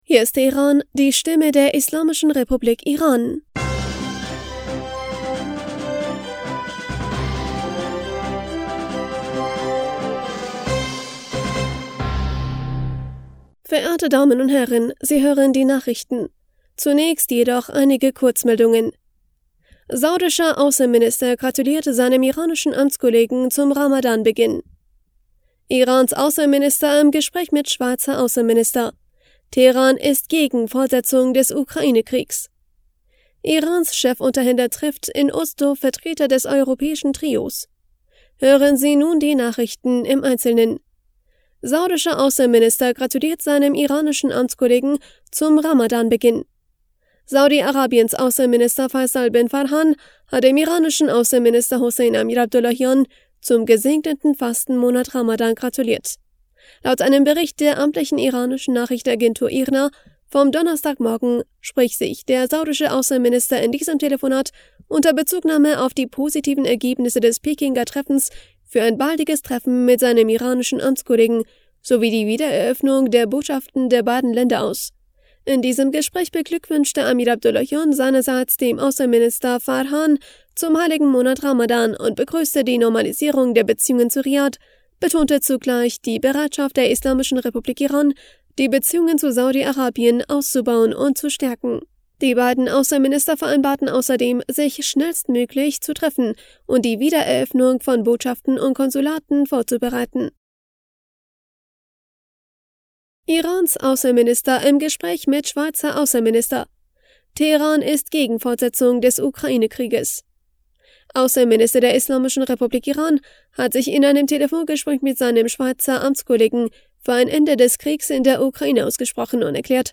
Nachrichten vom 23. März 2023